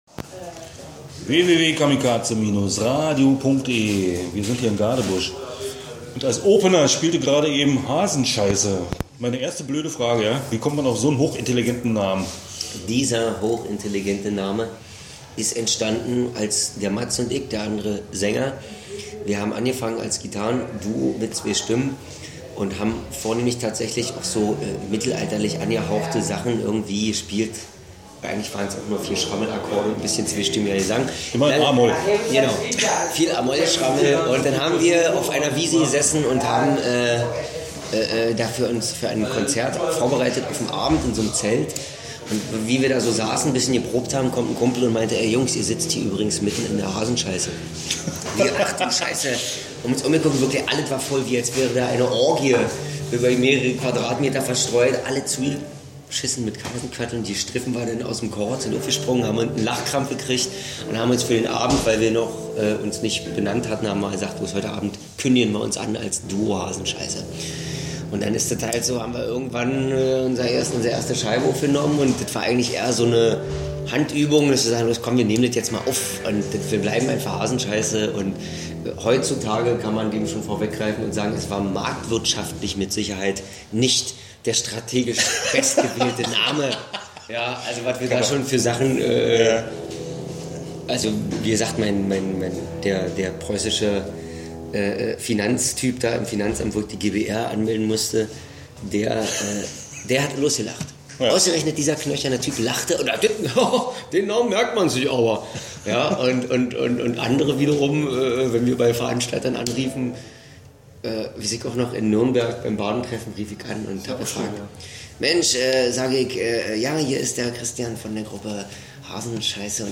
Interview Hasenscheisse 2014-Sommerschlacht